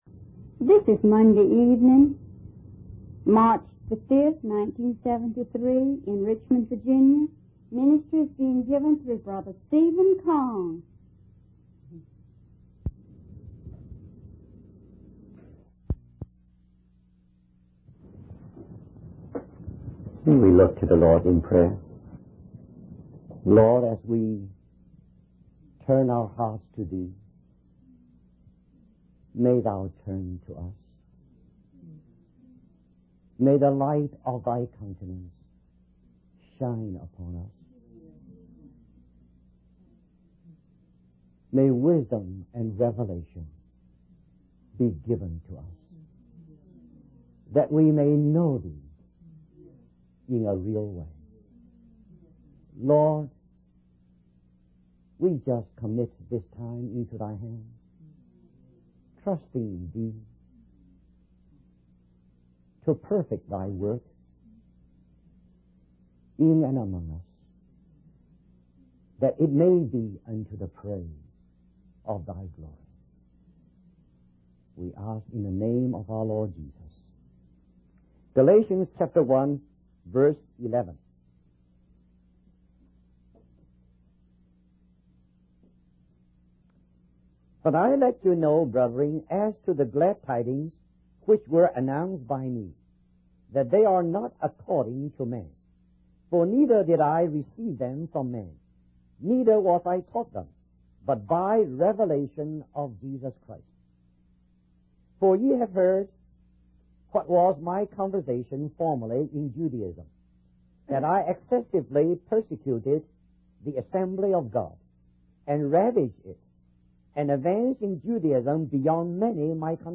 In this sermon, the preacher emphasizes that the eyes of worldly people are blinded by the God of this world, preventing them from experiencing the power of the gospel. He uses the analogy of a covered air conditioner to illustrate how even though something is present, it cannot be seen. The preacher highlights that the gospel of Jesus Christ leaves believers with nothing to do because Jesus has already done it all.